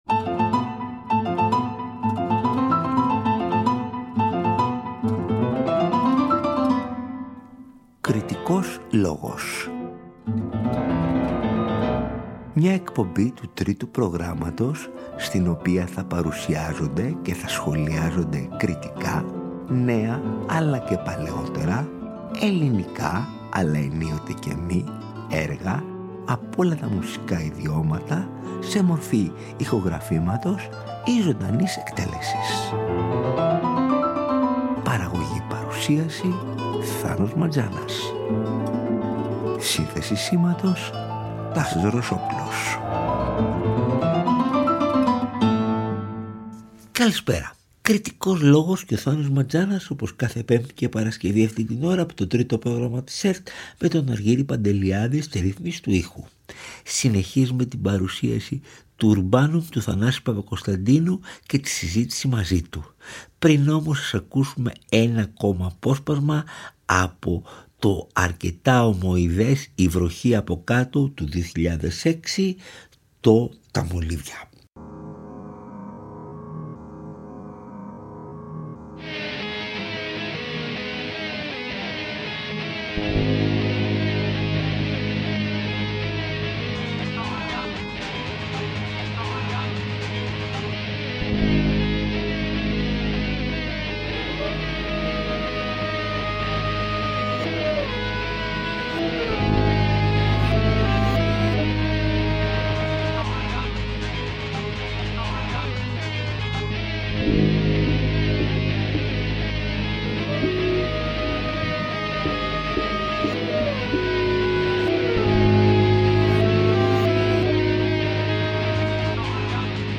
Το νέο, οργανικό, δίσκο του Θανάση Παπακωνσταντίνου “Urbanum” παρουσιάζει και αναλύει μαζί με τον δημιουργό